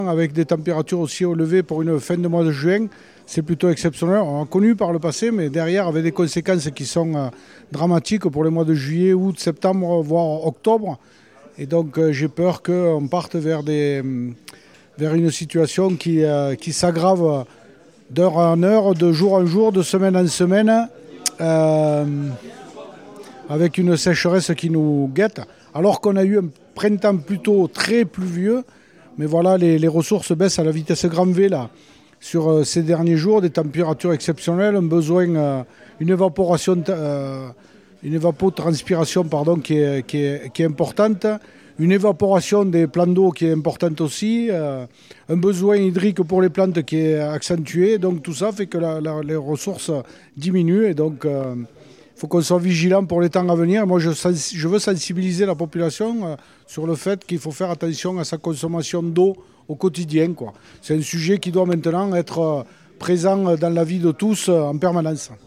» Voilà comment Laurent Suau, président de la Lozère a commencé sa prise de paroles hier midi lors du conseil départemental. L’élu lozérien s’inquiète de l’état actuel de l’hydrologie et des réserves en eau du département.